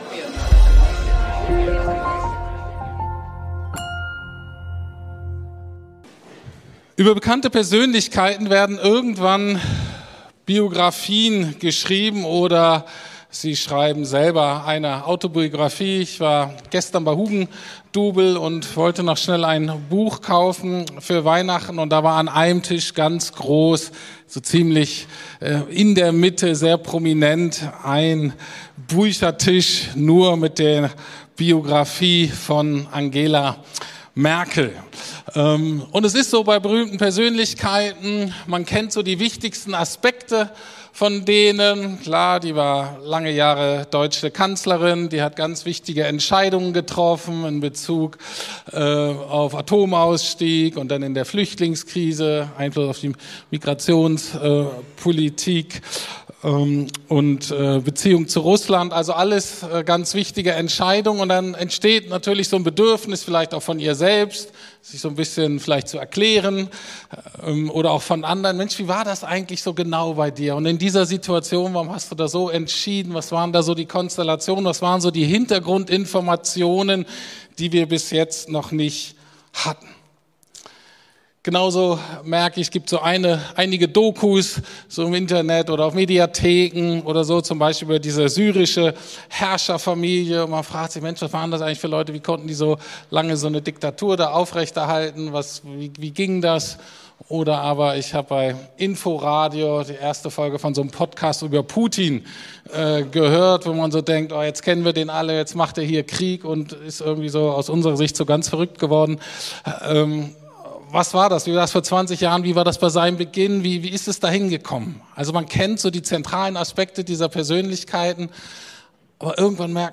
Predigten der LUKAS GEMEINDE
Predigten der LUKAS GEMEINDE in Berlin Schöneberg. Wir leben nach dem Motto "Beziehung tut gut".